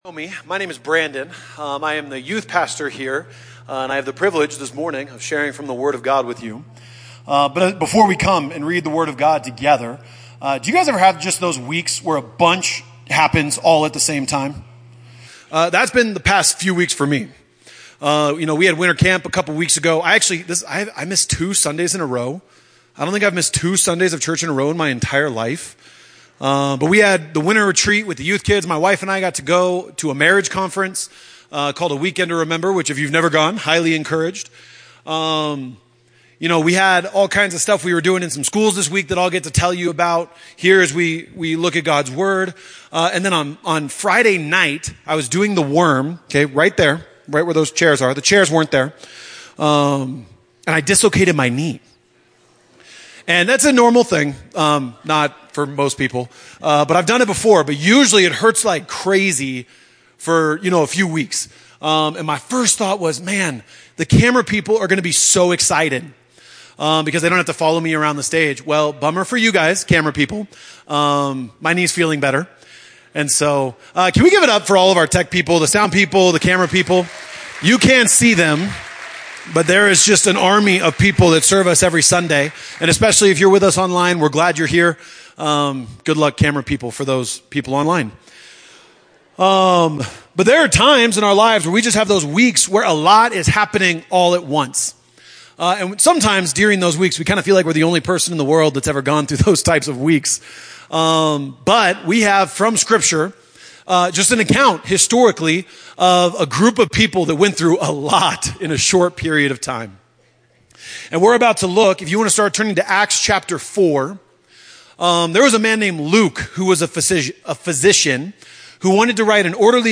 Acts 4:18 - 31 Guest Speaker